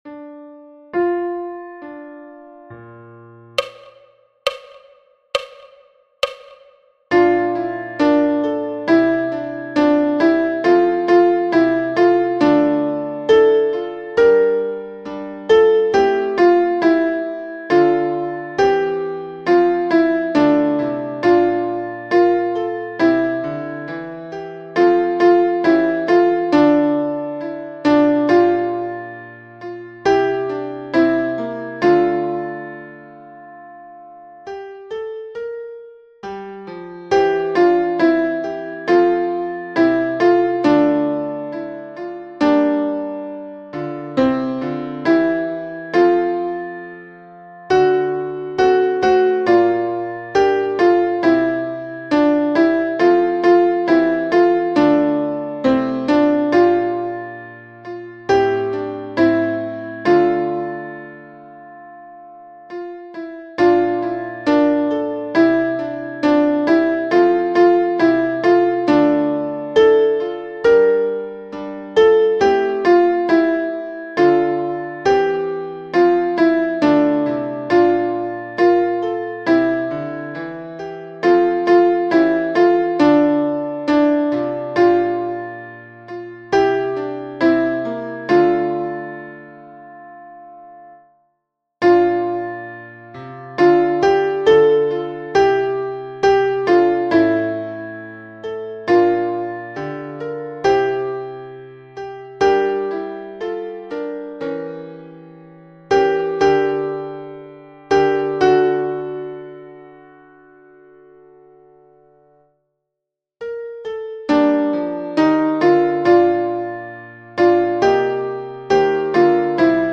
night-alto.mp3